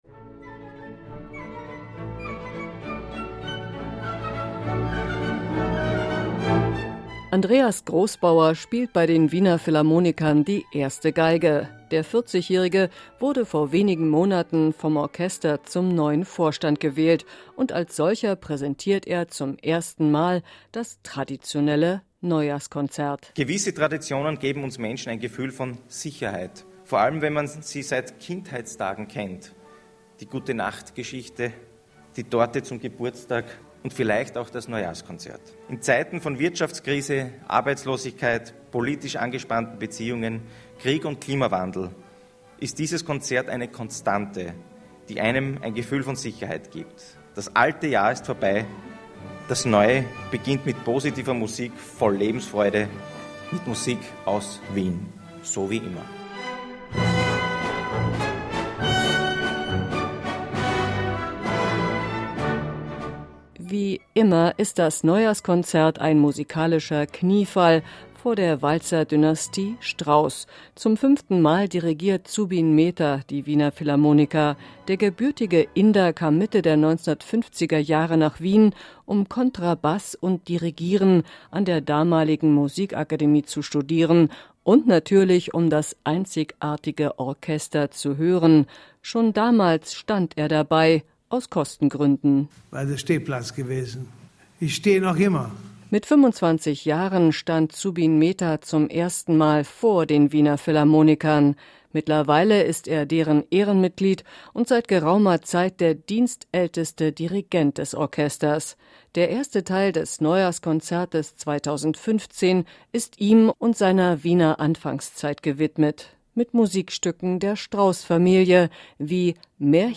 berichtet